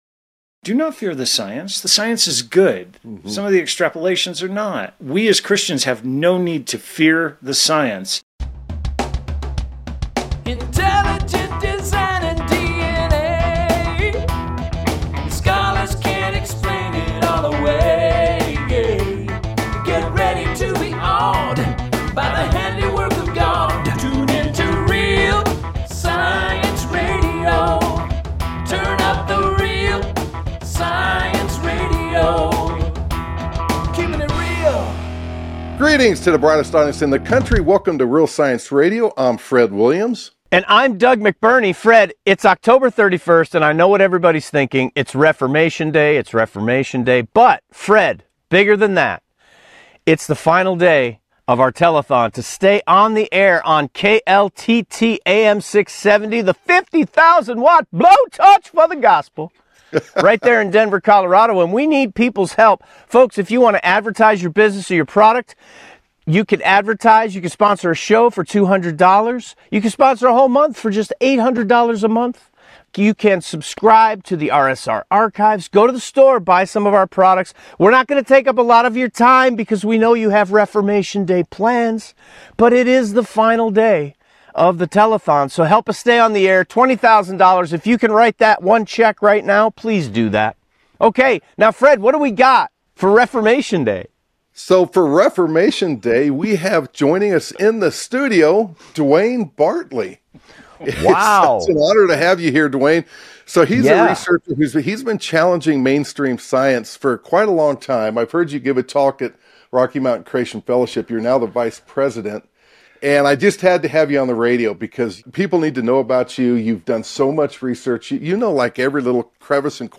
Friday's Broadcast